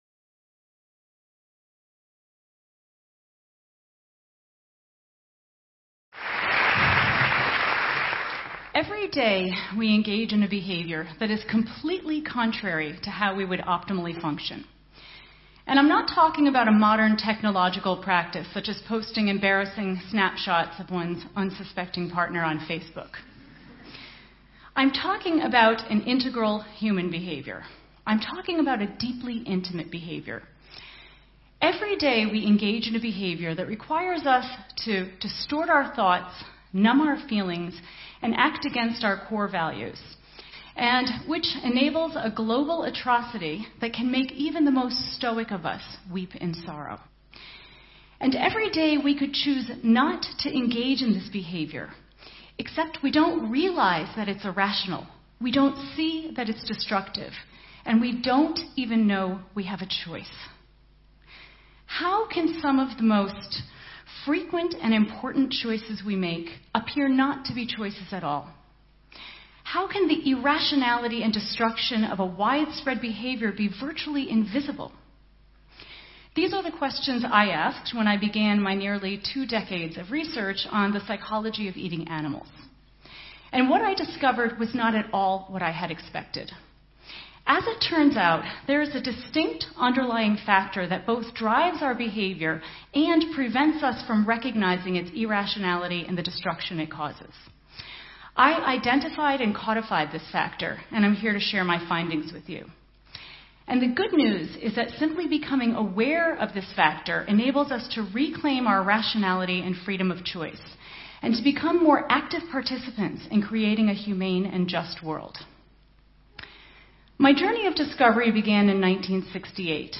The following is the transcript of Dr. Melanie Joy’s, an American social psychologist and vegan activist, talk on Beyond Carnism and Toward Rational, Authentic Food Choices at TEDxMünchen.